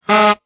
Play, download and share Laa-Laa Parp original sound button!!!!